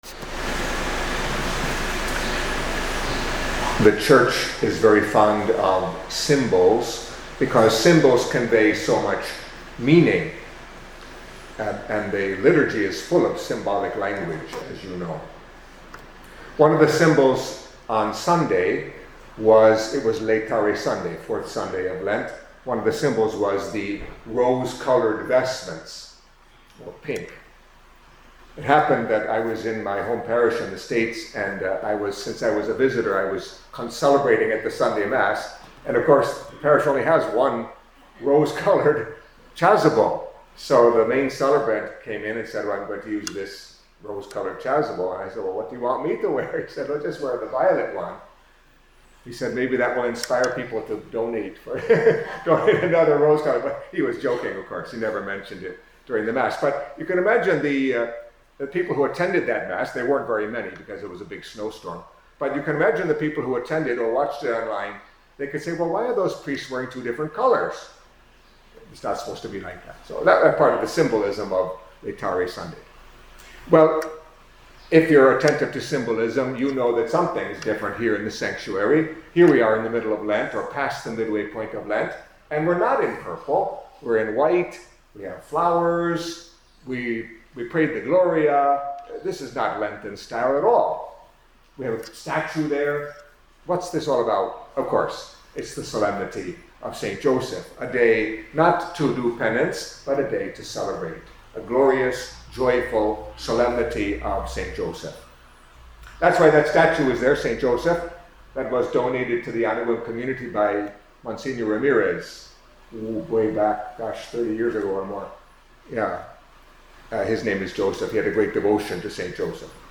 Catholic Mass homily for Solemnity of Saint Joseph, Spouse of the Blessed Virgin Mary